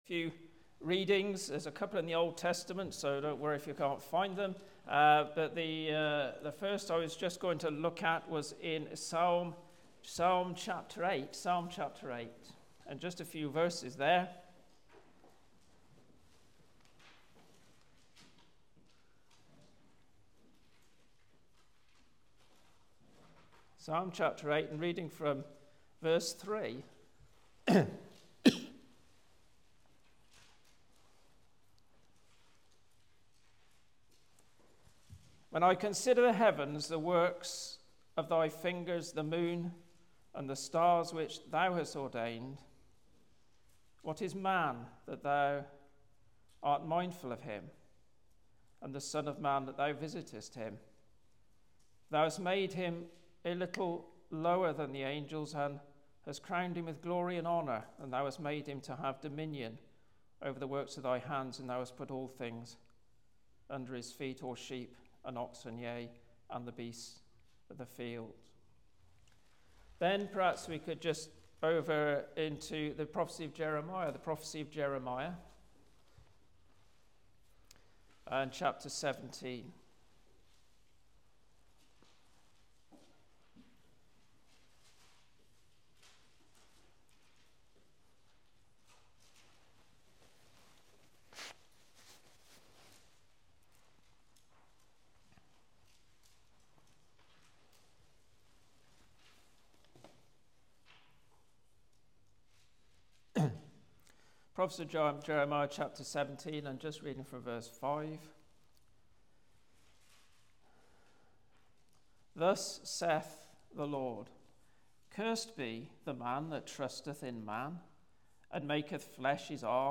This world teaches us to keep our heads down, plough on with day-to-day life, chasing pleasures and satisfaction – instead of listening to the Creator God. Listen to this sermon to discover His message to you.